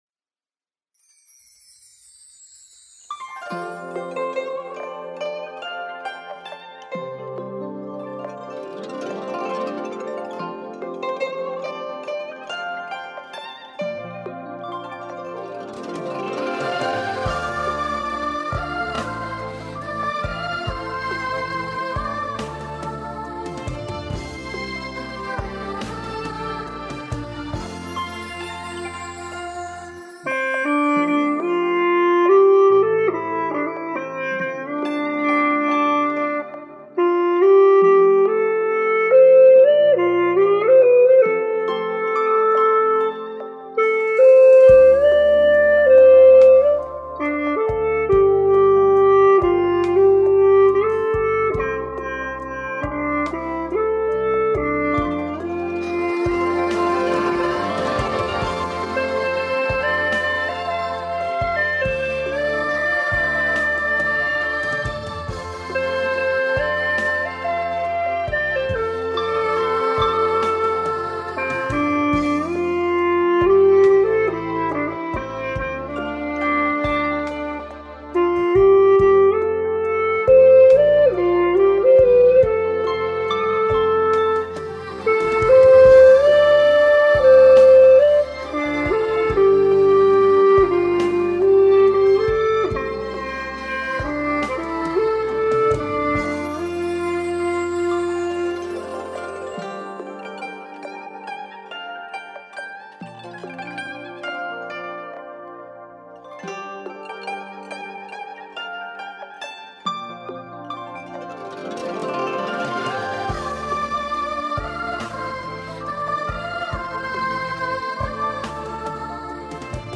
衔接得很棒，大F的音色也很美！
吹的很深情，葫芦音色很好。